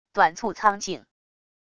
短促苍劲wav音频